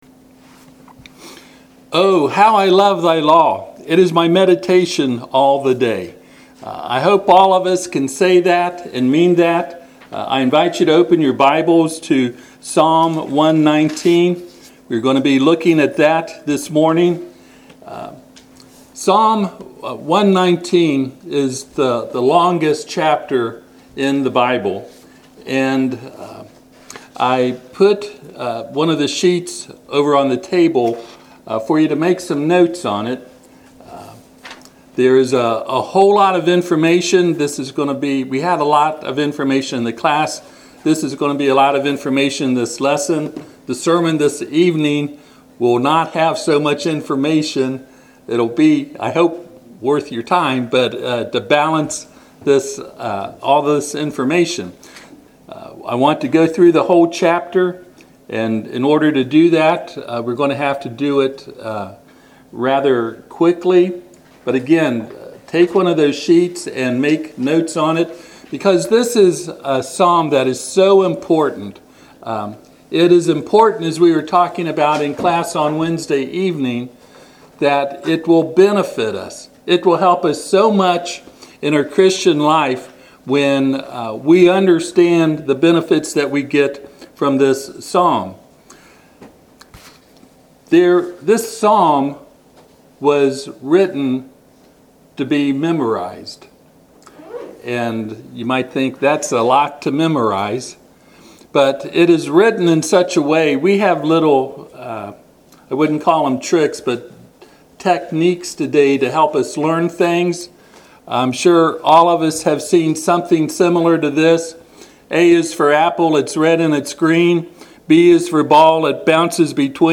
Psalm 119:97 Service Type: Sunday AM https